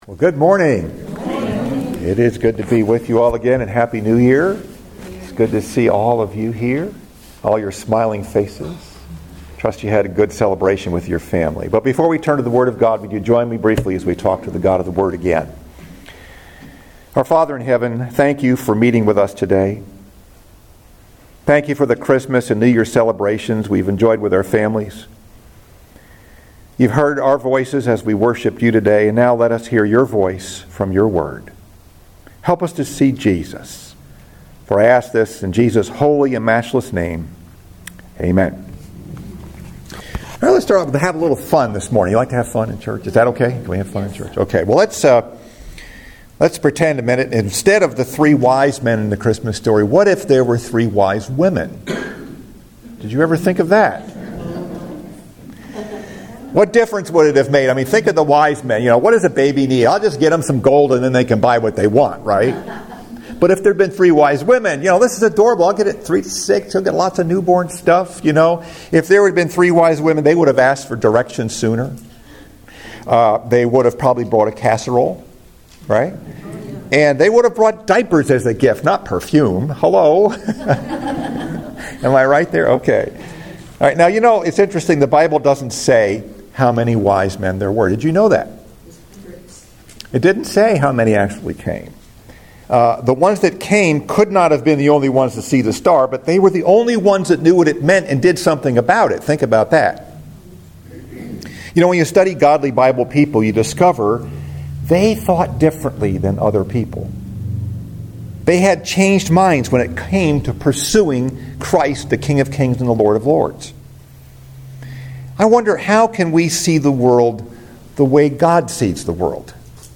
Message: “Seeker or Sitter?” Scripture: Matthew 2:1-8 SECOND SUNDAY AFTER CHRISTMAS